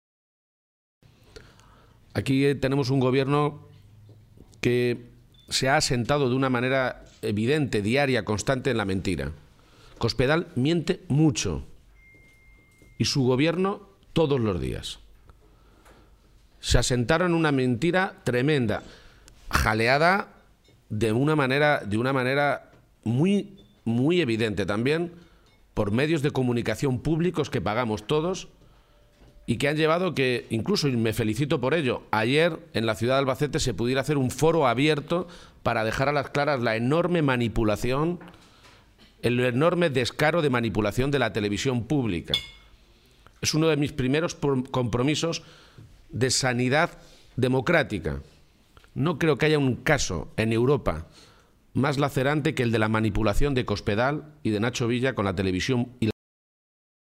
Cortes de audio de la rueda de prensa
Audio García-Page desayuno informativo en Albacete-2